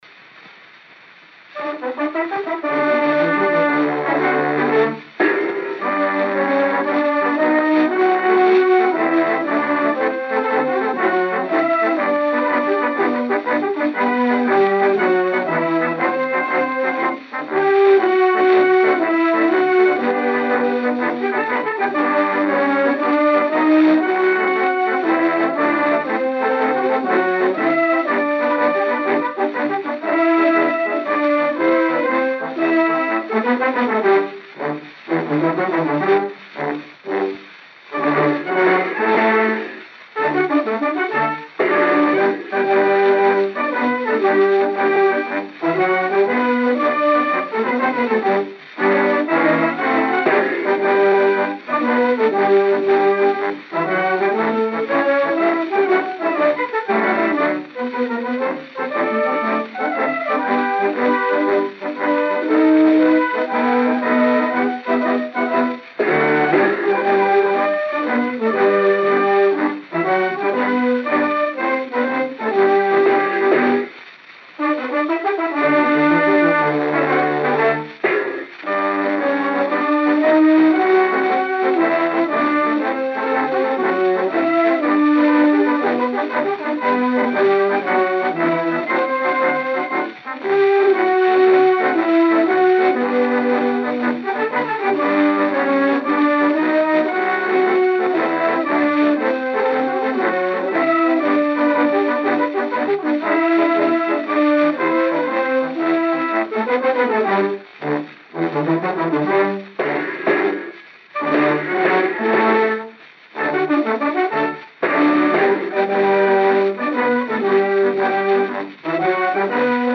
O gênero musical foi descrito como "Dobrado".